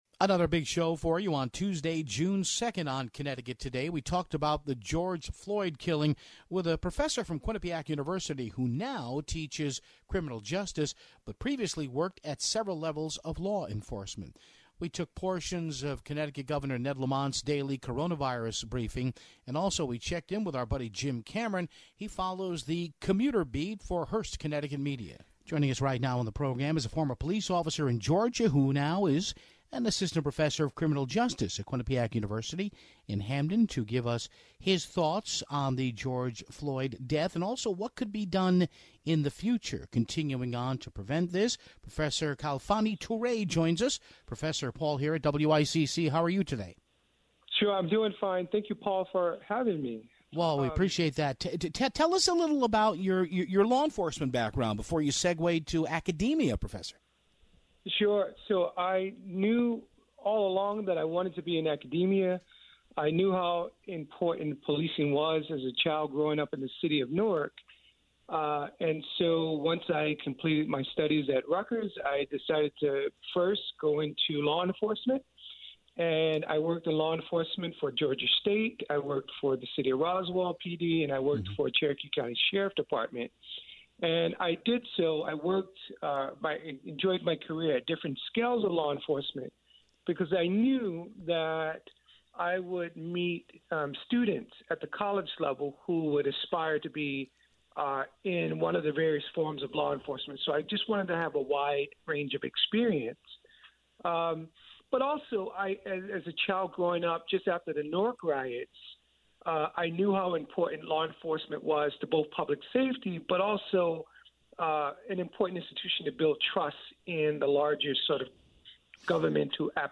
We carried Governor Ned Lamont's coronavirus and civil rights press conference